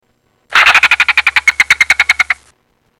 На этой странице собраны разнообразные звуки хорьков: от игривого попискивания до довольного урчания.
Вопль хорька